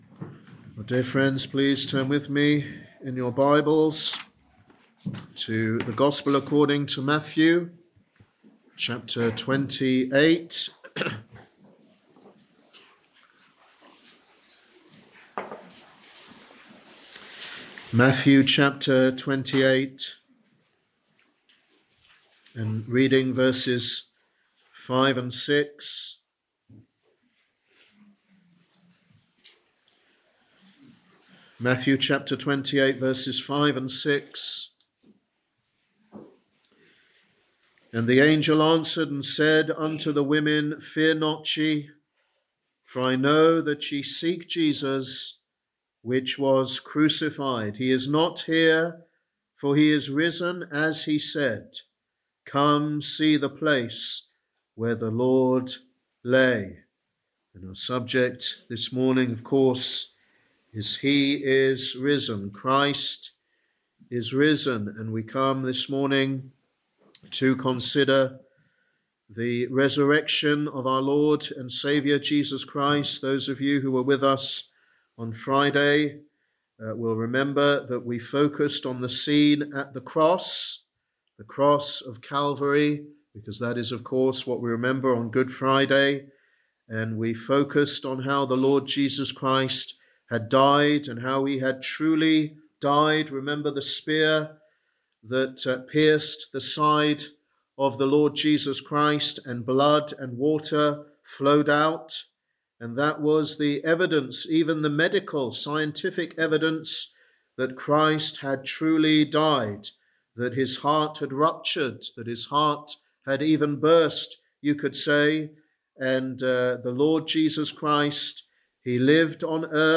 Sermon: He is Risen
Easter Morning Service 2025 Matthew 28.5-6